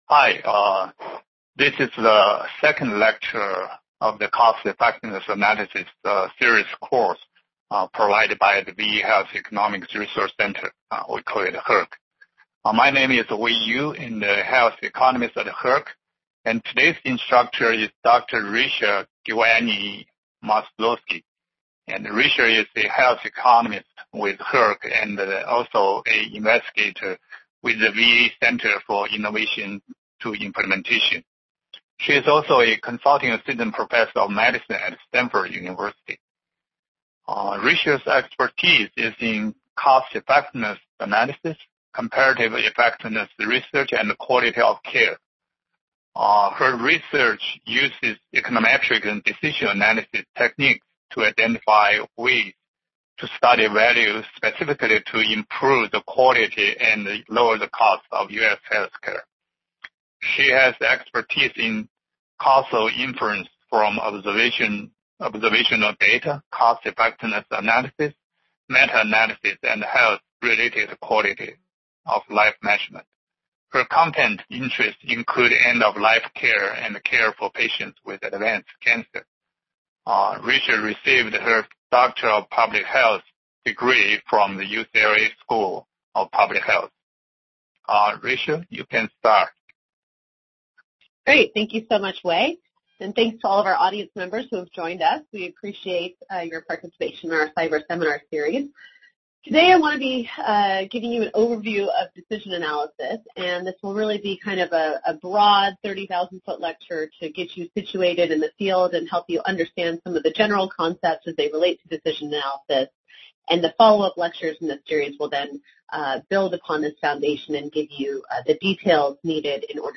HERC Cost Effectiveness Analysis Seminar